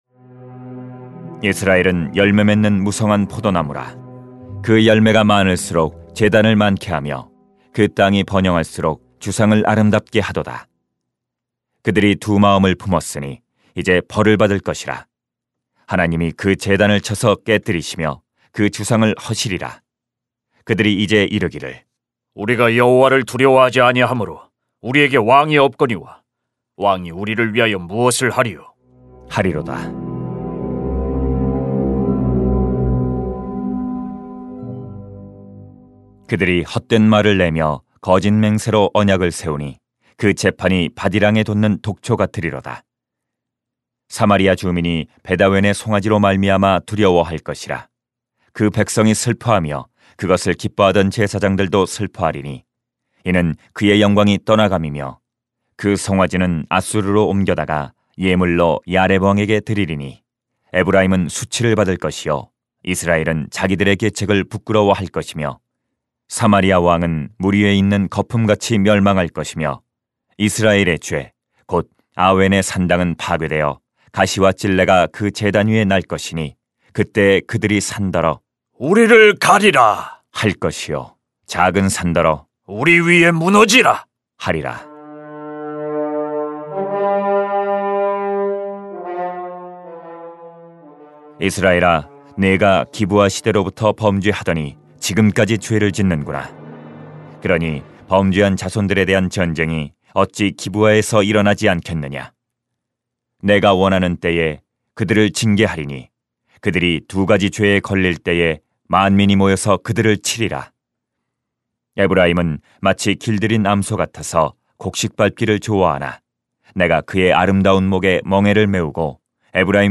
[호10:1-15] 공의를 심고 인애를 거두어야 합니다 > 새벽기도회 | 전주제자교회